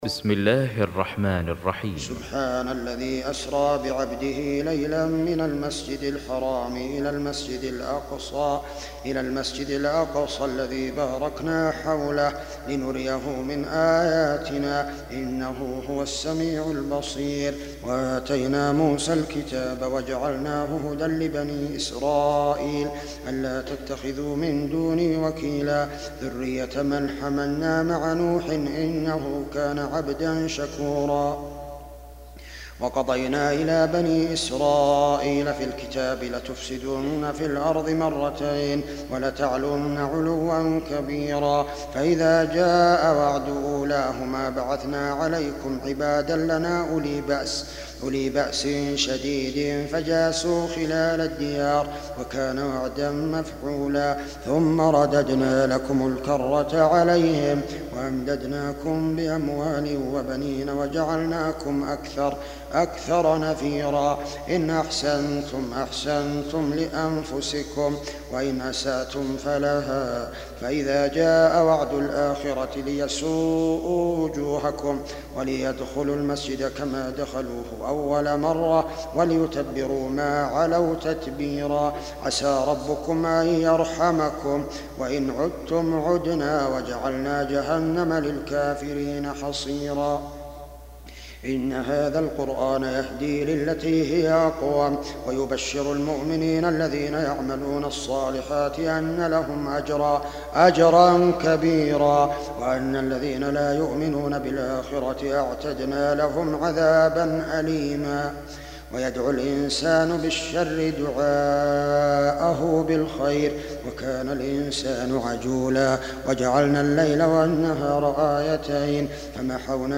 17. Surah Al-Isr�' سورة الإسراء Audio Quran Tarteel Recitation
Surah Repeating تكرار السورة Download Surah حمّل السورة Reciting Murattalah Audio for 17.